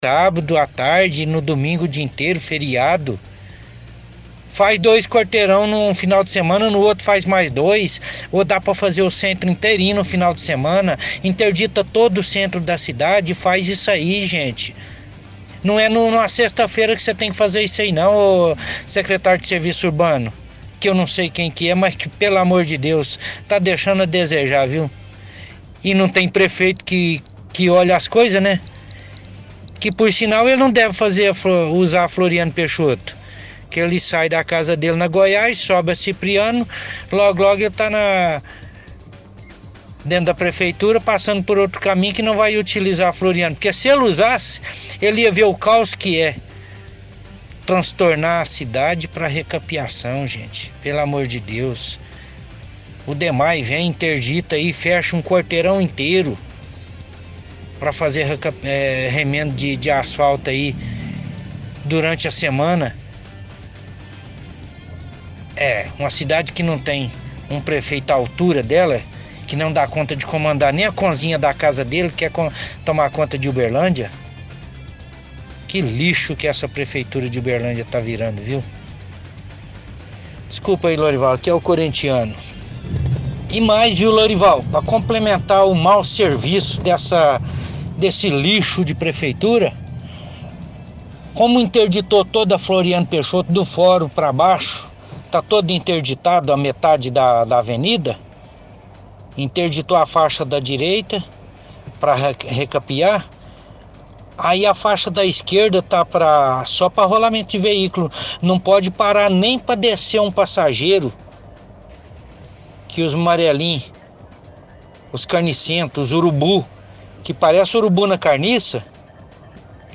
– Ouvinte, motorista de aplicativo, reclama de avenidas interditadas para recapeamento em dias de semana, diz que tinham que interditar as vias fins de semana e feriados. Cobra o secretário de obras.